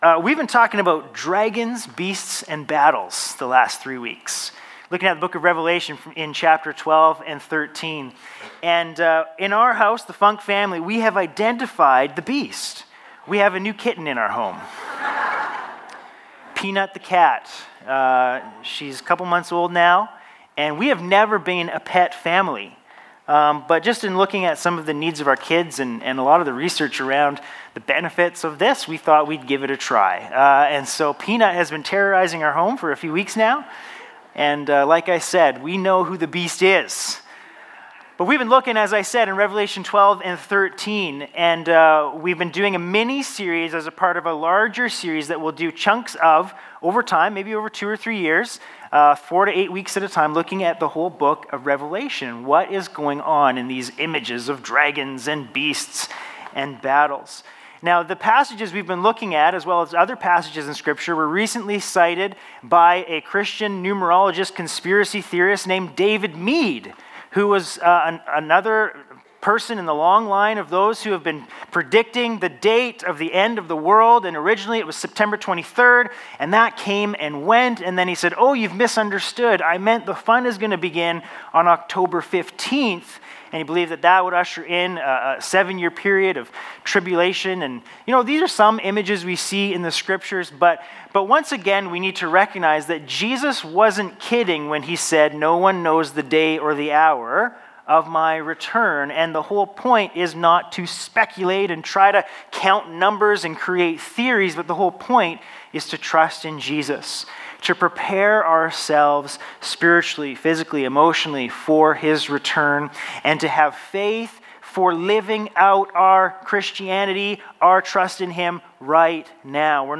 Sermons | Bethel Church Penticton